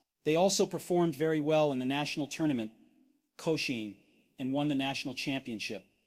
→ /ðeɪ ˈɔlsoʊ pərˈfɔrmd ˈvɛri wɛl ɪn ðə ˈnæʃənəl ˈtʊrnəmənt, koʊʃiˈɛn, ænd wʌn ðə ˈnæʃənəl ˈtʃæmpiənˌʃɪp!/